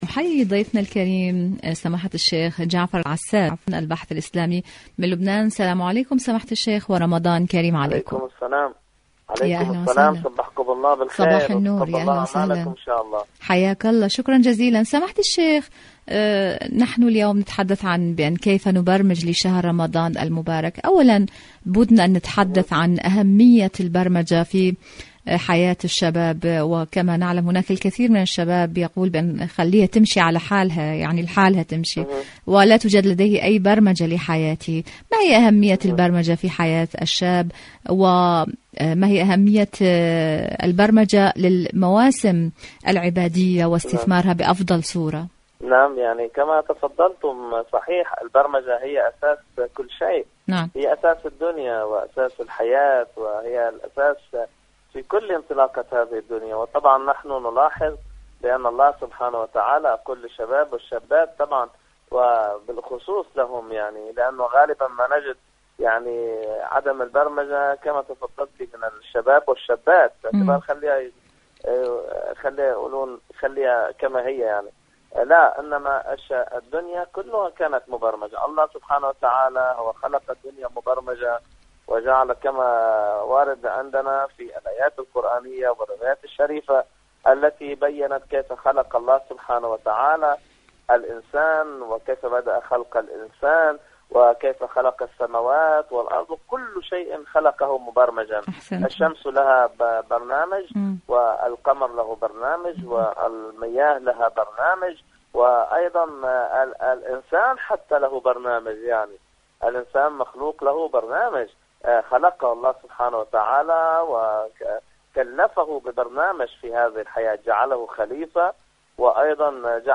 مقابلات مقابلات إذاعية برامج إذاعة طهران العربية برنامج دنيا الشباب الشباب الشباب وكيفية البرمجة لشهر رمضان شهر رمضان المبارك شاركوا هذا الخبر مع أصدقائكم ذات صلة عاشوراء أيقونة الثوار..